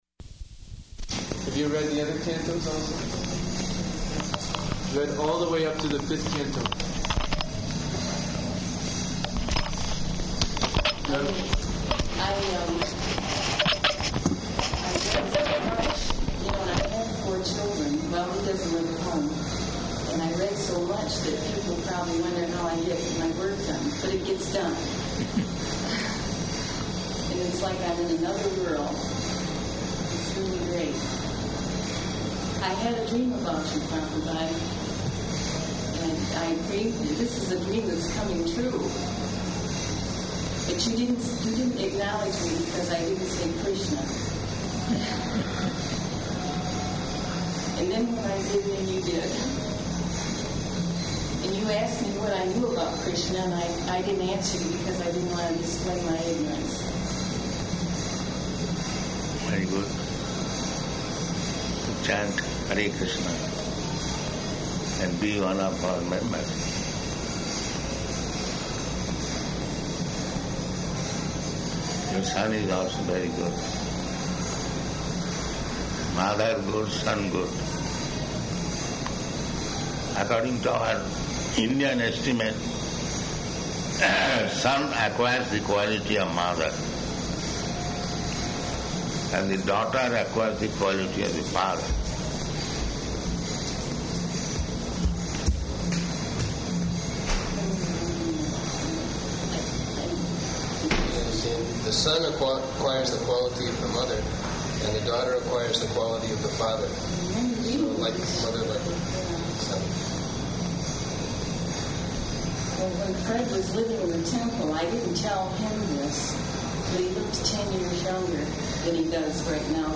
Room Conversation with Mother and Son
-- Type: Conversation Dated: June 13th 1976 Location: Detroit Audio file